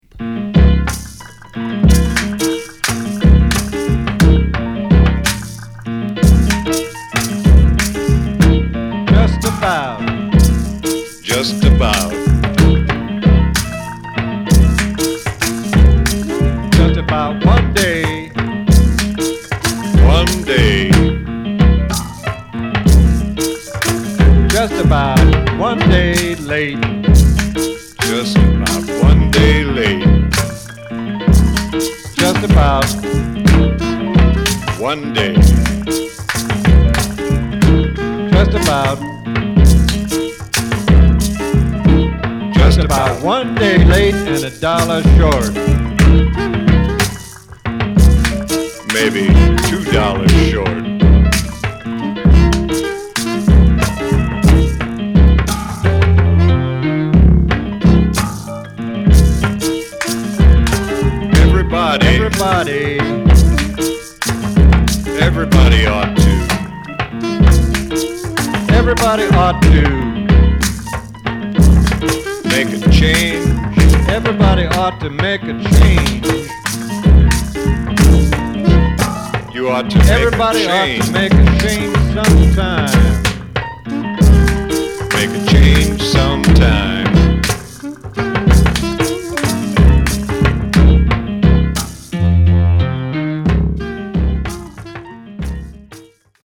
キーワード：即興　ミニマル　エレクロアコースティック